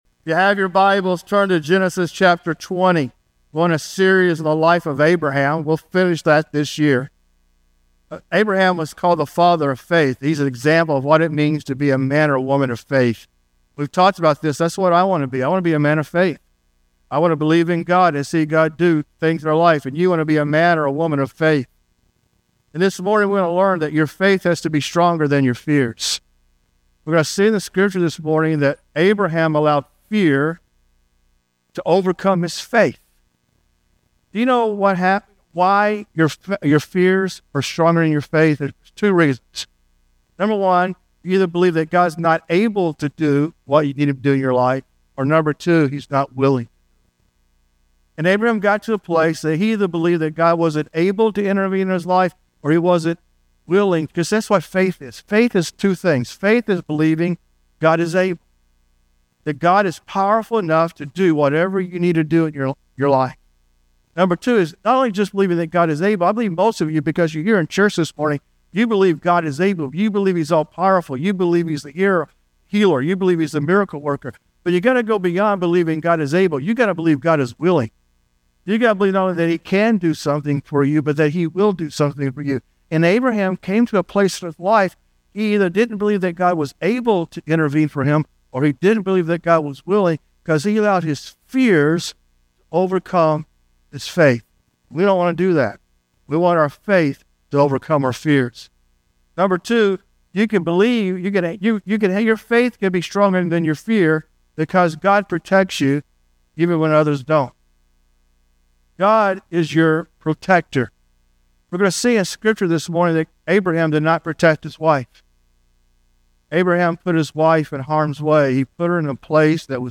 Sermons | First Assembly of God